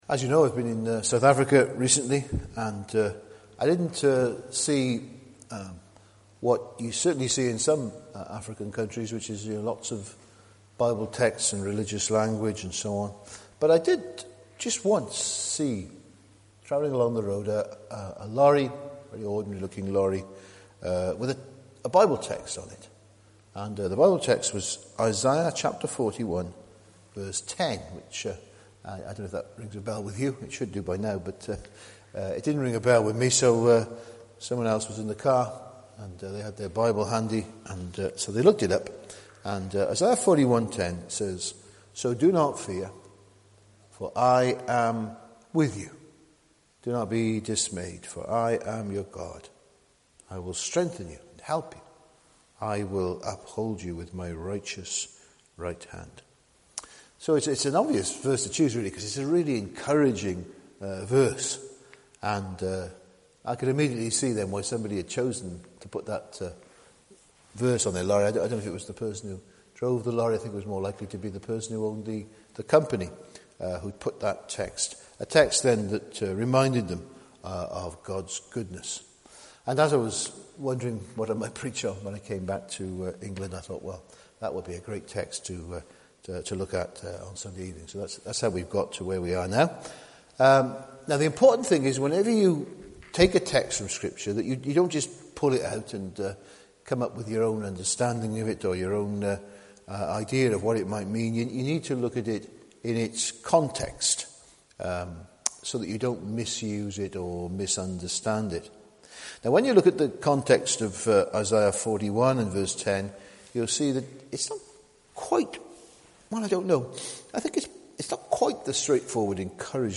That evening sermon is only 24 minutes long so if you want to check it out it's here .